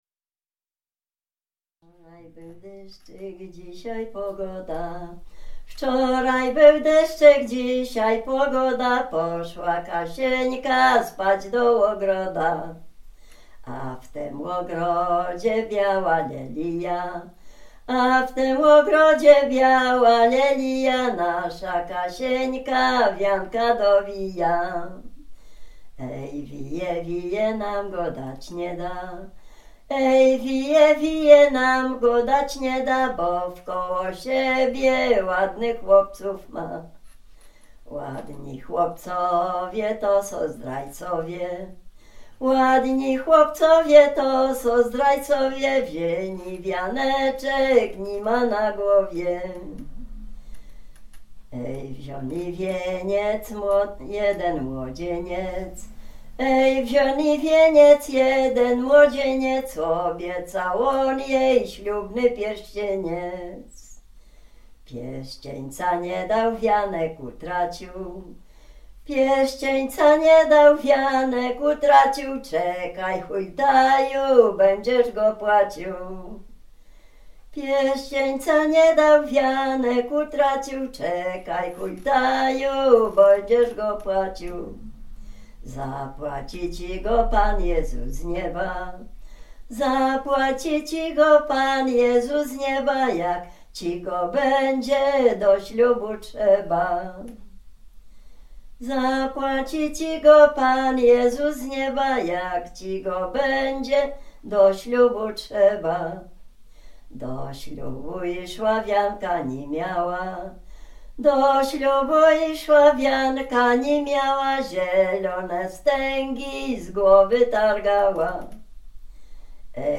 Dolny Śląsk, powiat bolesławiecki, gmina Osiecznica, wieś Przejęsław
liryczna miłosna